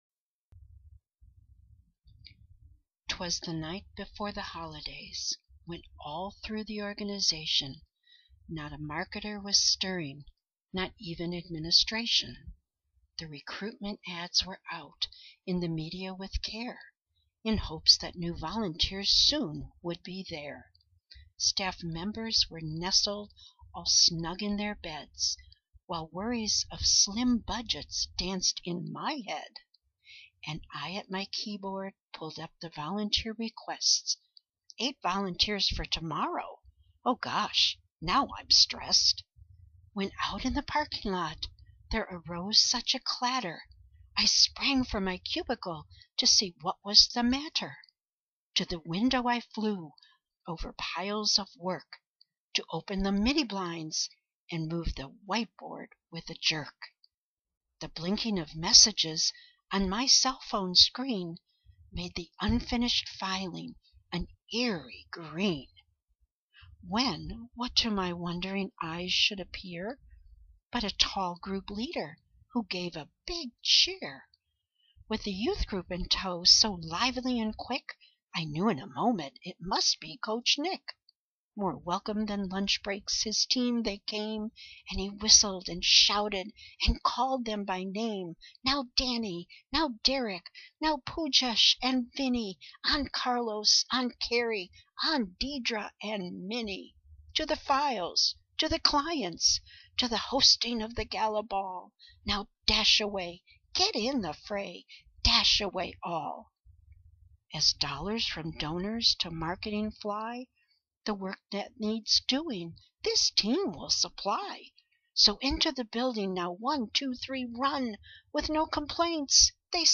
Grab a cup of hot cocoa and please allow me to read my wish for your holiday season: